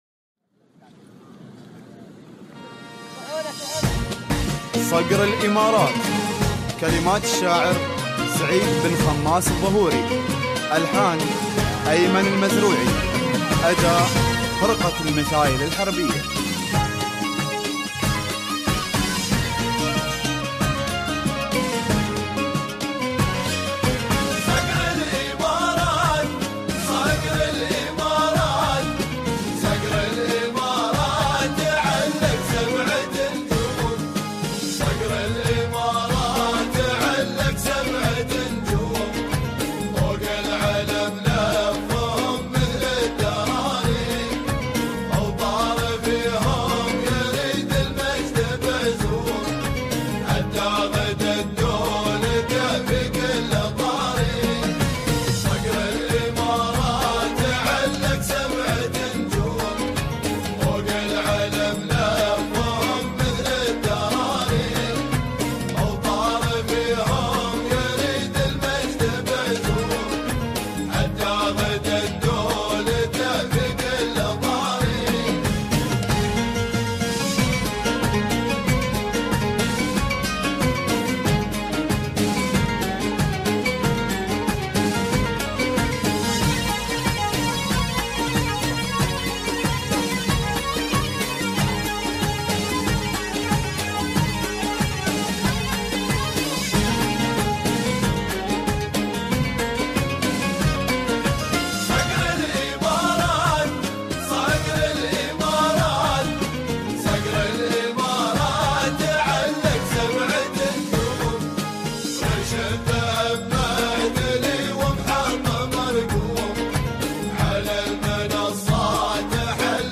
Carpeta: musica arabe mp3